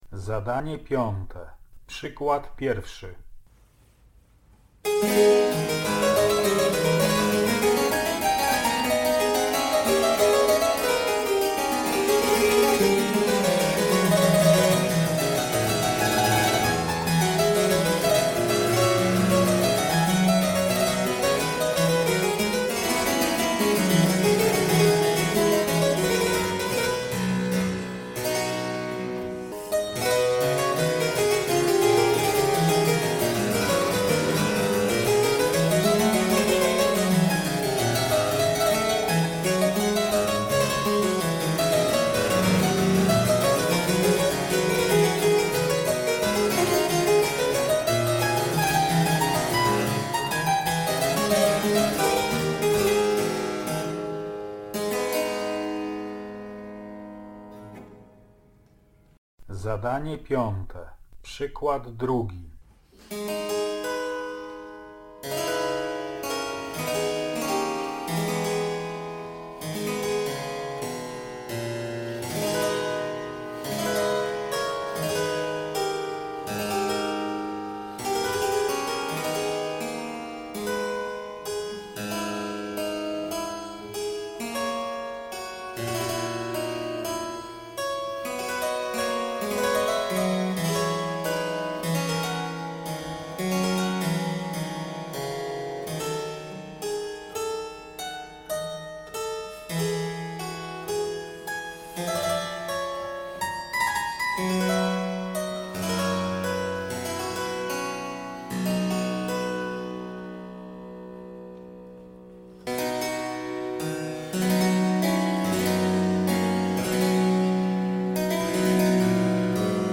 Nagranie nie uwzględnia znaków repetycji. Występujące w nagraniu dodane elementy ornamentacji są zgodne z improwizacyjną praktyką wykonawczą baroku.